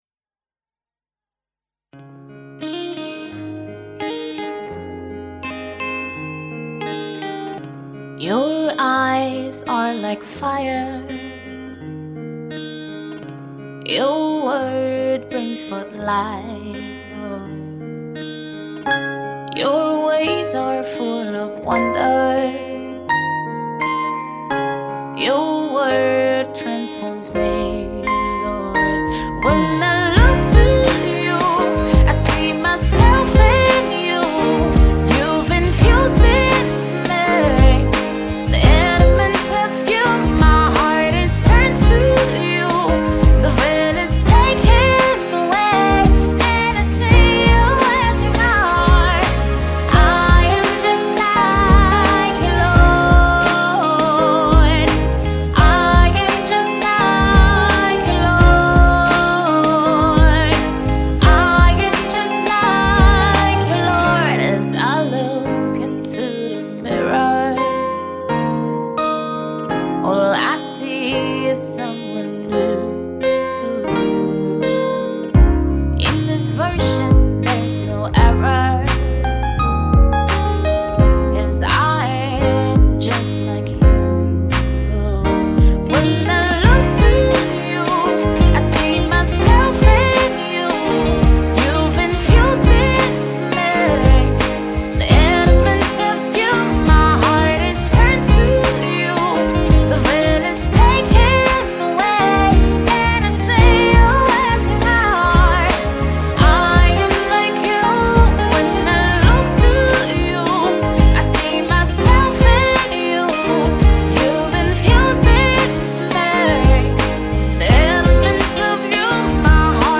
Nigerian Christian singer, songwriter, and recording artist